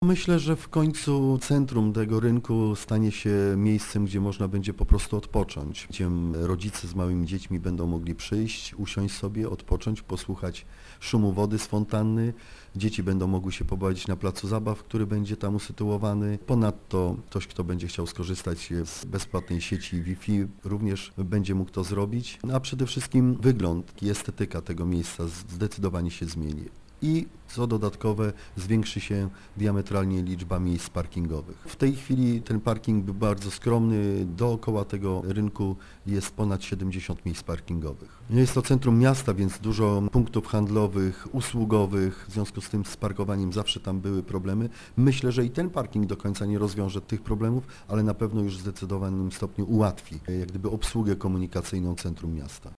- Efekty tej inwestycji będą naprawdę widoczne, a korzyści z niej wynikające, nie do przecenienia - dodaje Jerzy Gąska: